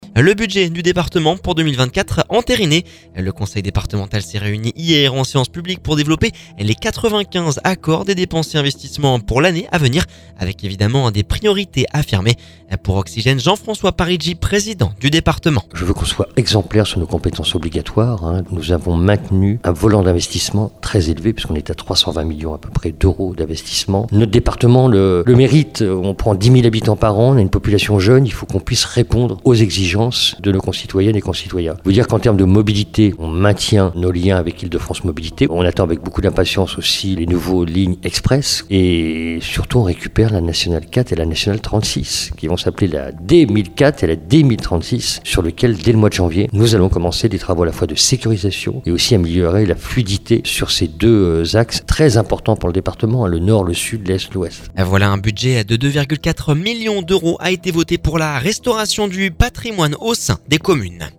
Pour Oxygène, Jean-François Parigi, président du Département…